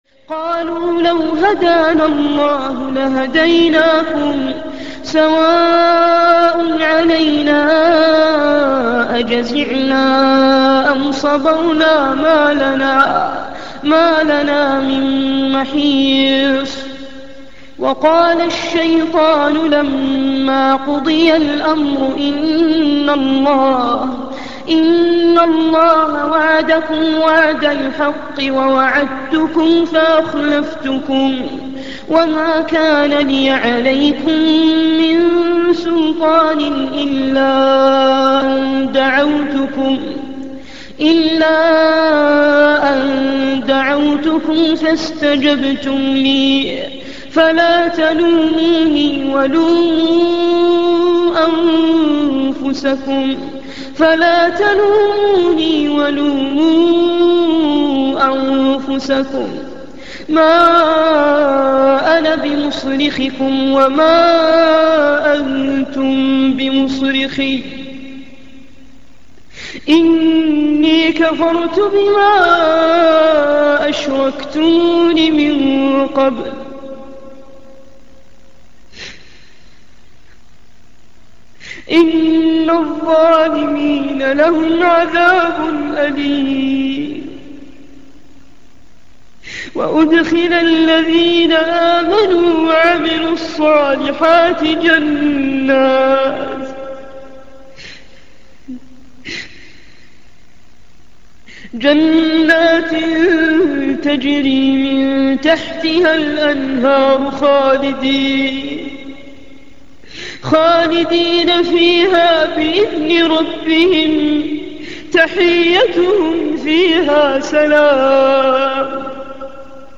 من هو هذا القارئ